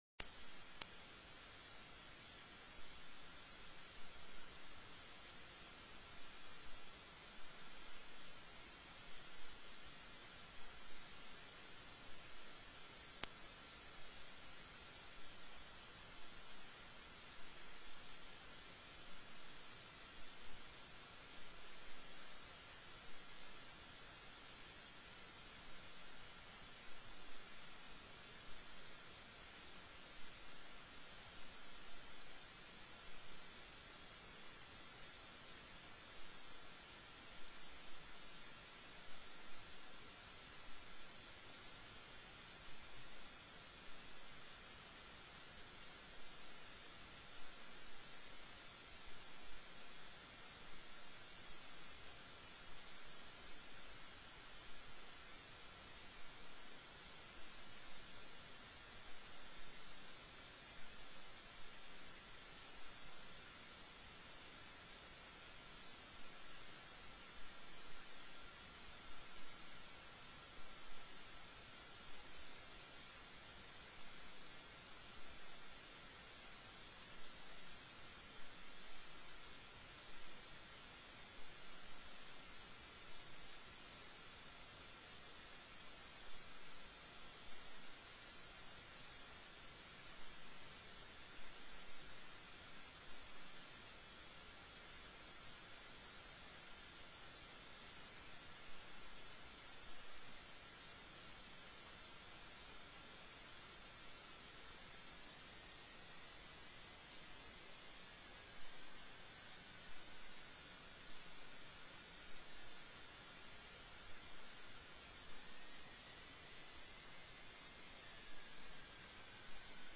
The audio recordings are captured by our records offices as the official record of the meeting and will have more accurate timestamps.
Considerations of Governor's Appointees: TELECONFERENCED